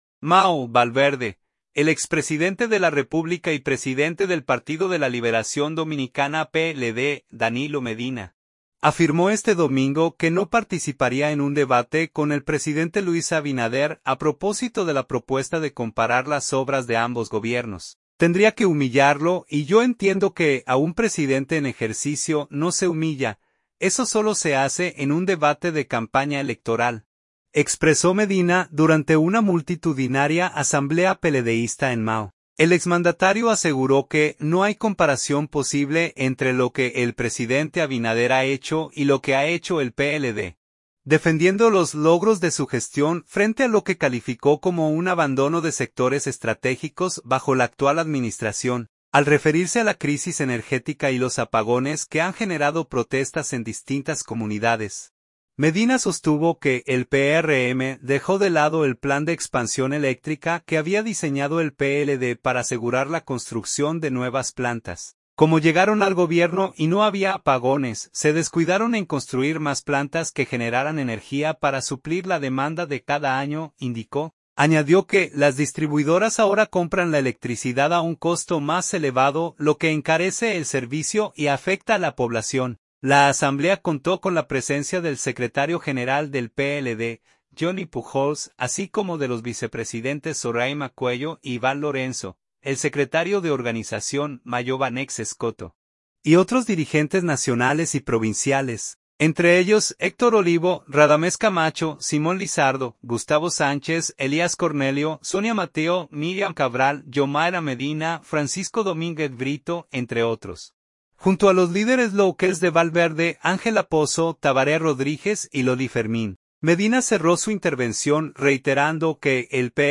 “Tendría que humillarlo, y yo entiendo que a un presidente en ejercicio no se humilla. Eso sólo se hace en un debate de campaña electoral”, expresó Medina durante una multitudinaria asamblea peledeísta en Mao.